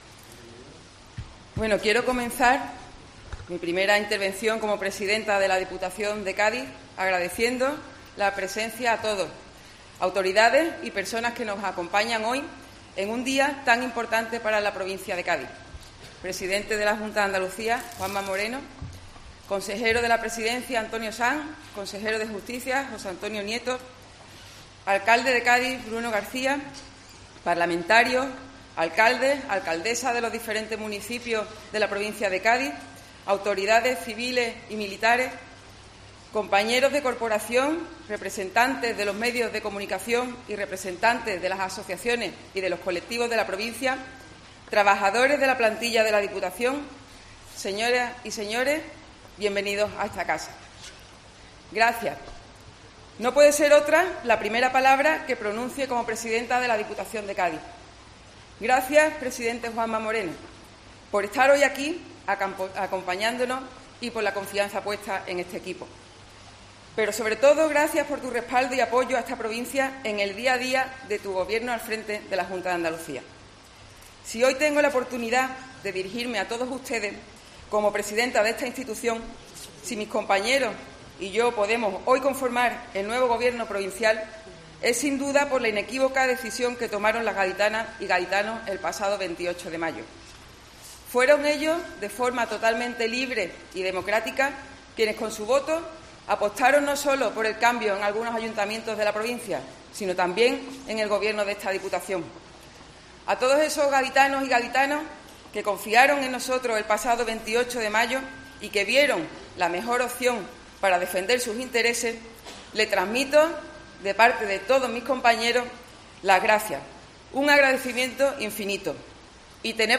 Discurso investidura Almudema Martinez del Junco, presidenta de la Diputación de Cádiz